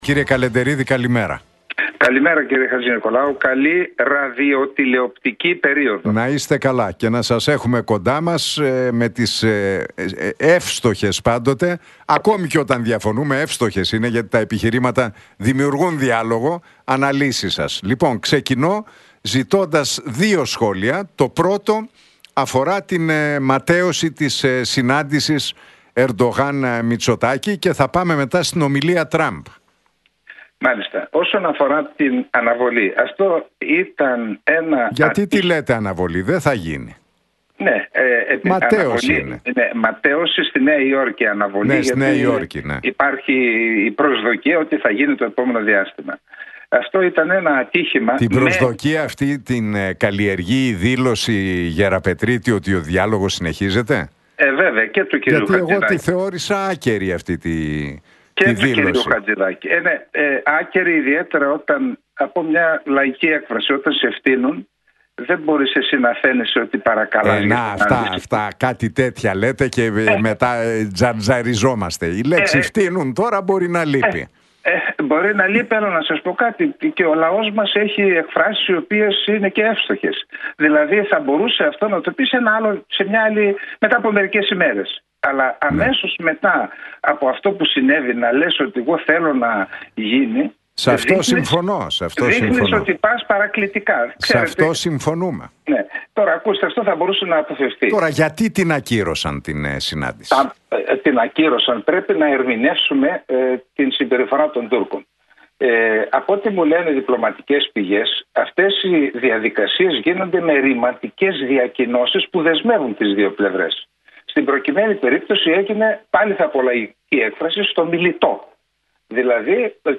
Για τις εξελίξεις στα ελληνοτουρκικά και την ματαίωση της συνάντησης Μητσοτάκη – Ερντογάν στην Νέα Υόρκη μίλησε Σάββας Καλεντερίδης στον Νίκο Χατζηνικολάου από την συχνότητα του Realfm 97,8.